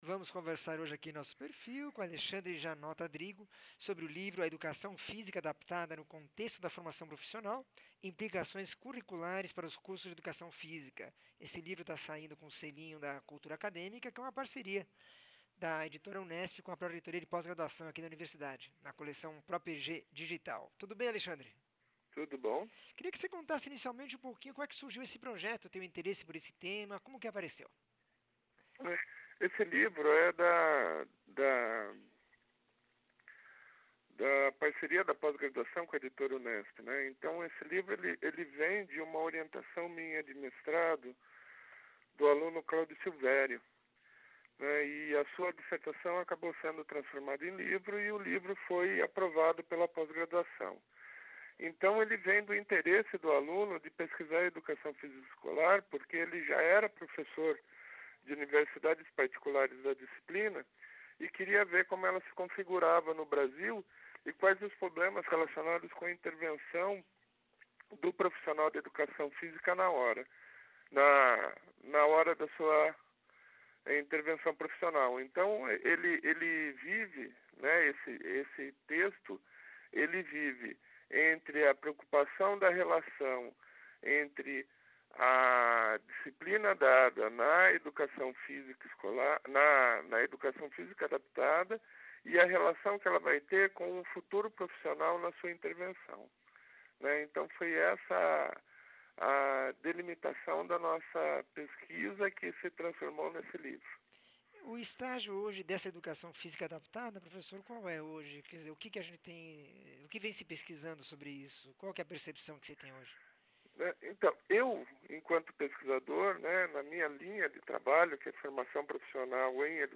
entrevista 1718